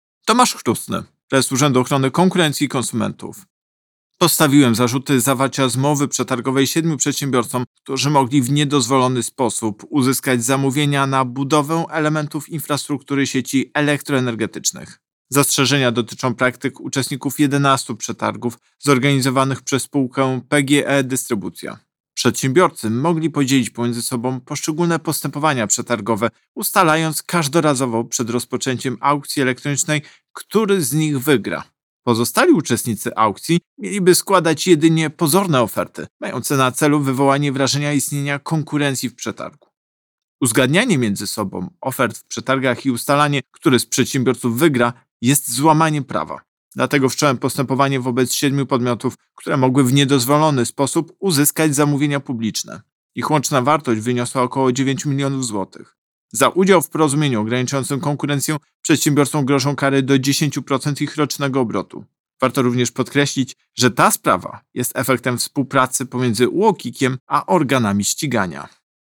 Wypowiedź Prezesa UOKiK Tomasza Chróstnego z 14 lutego 2023 r..mp3